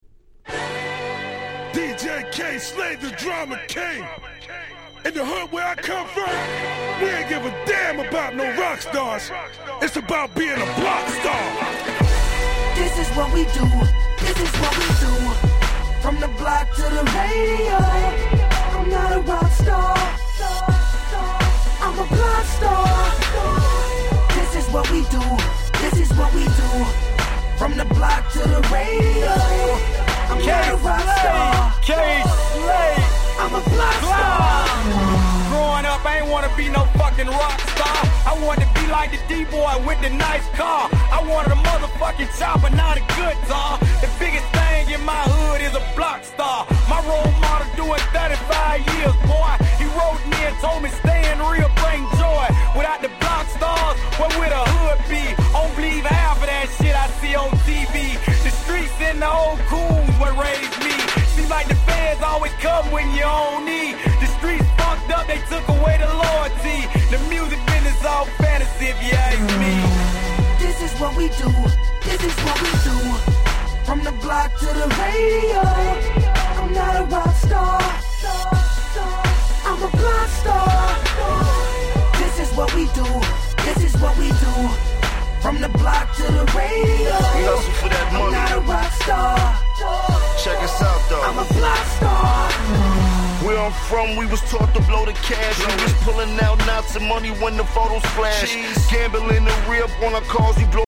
10' Nice Hip Hop !!